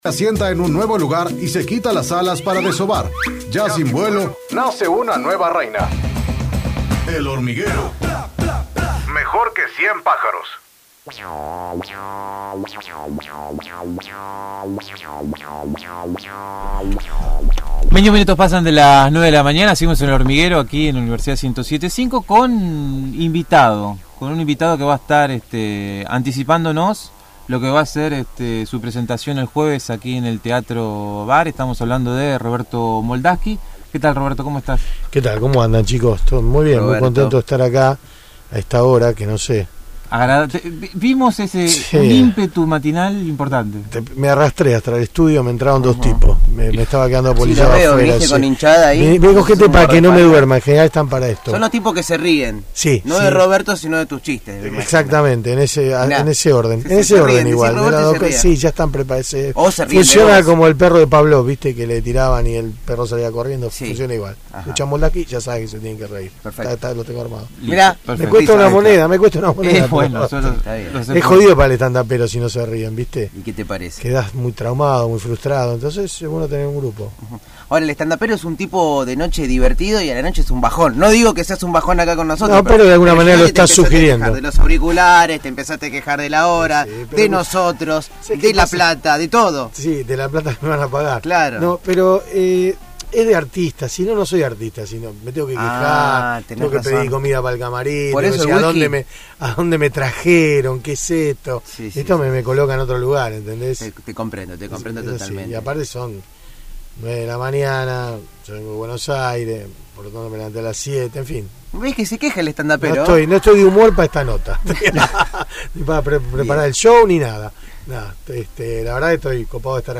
Roberto Moldavsky, cómico especialista en humor judío, visitó el estudio de «El hormiguero» en la previa de su primera presentación en la ciudad de La Plata, que será el jueves 14 de abril a las 21 horas, en El Teatro Bar (43 entre 7 y 8).